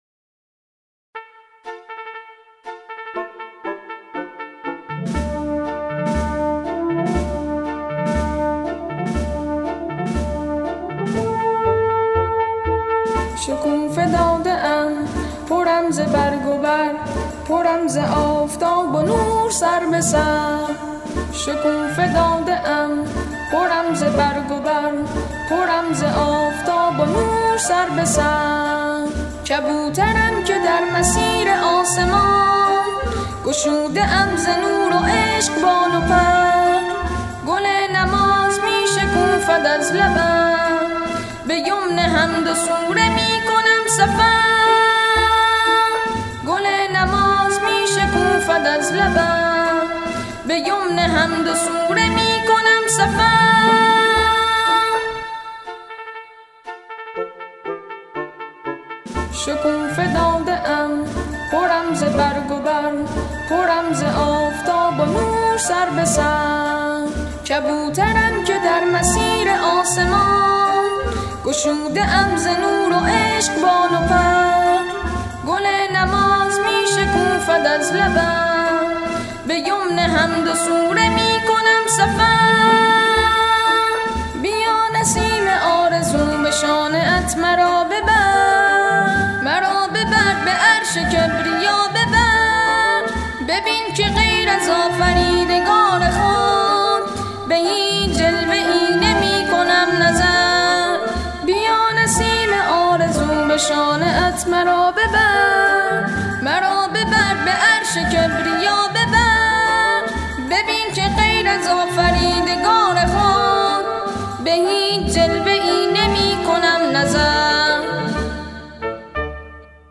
تکخوان پسر ناشناس اجرا می‌کند.